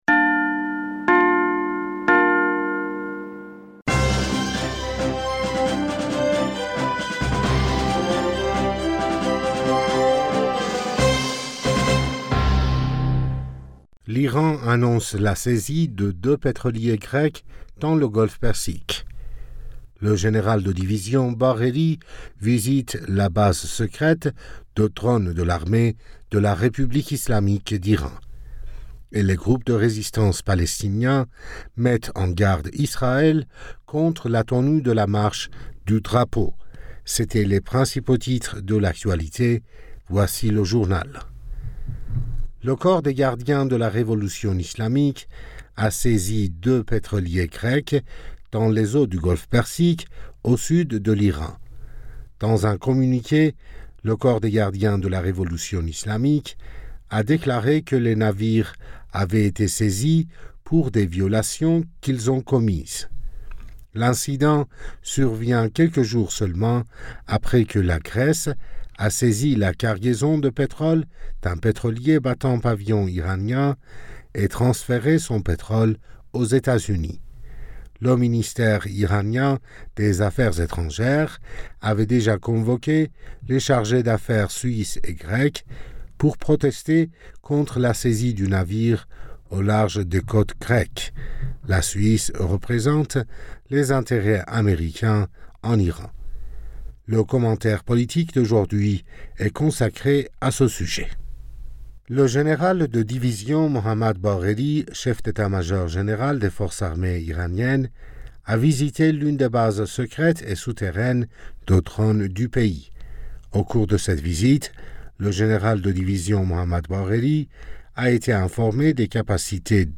Bulletin d'information Du 28 Mai 2022